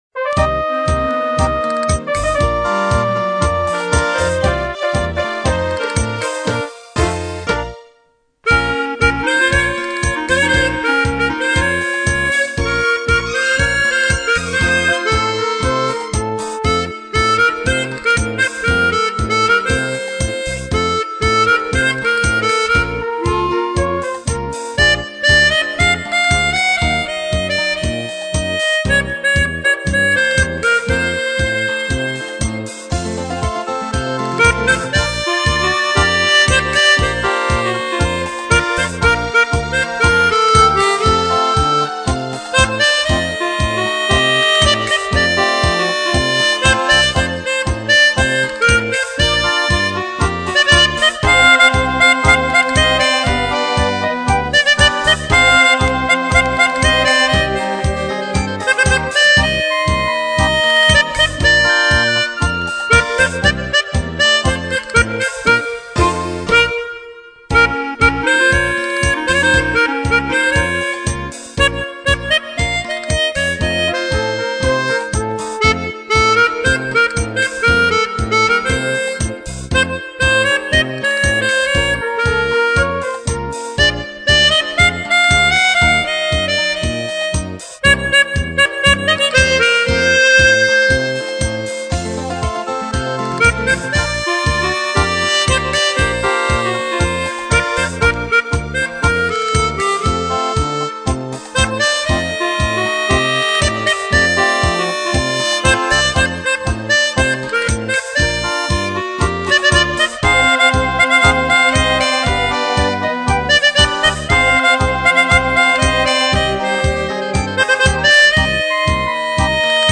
Il n'aurait jamais attaqué les notes aussi sèchement.